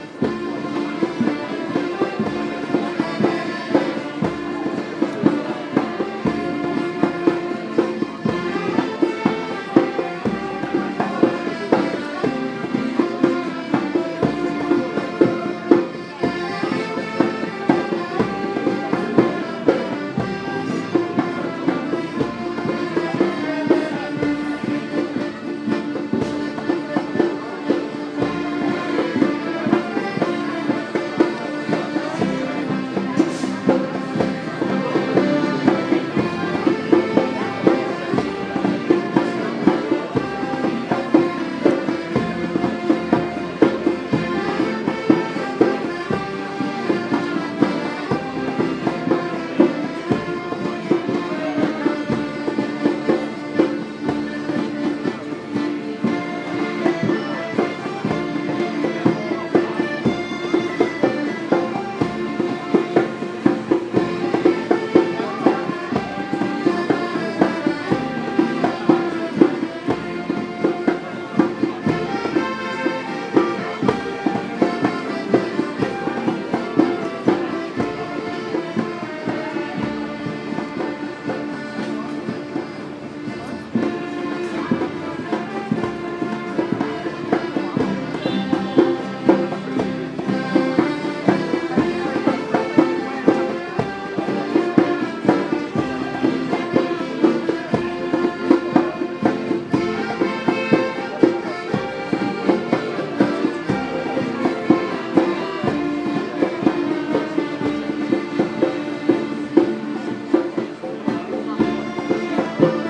Music near Bytheways Cafe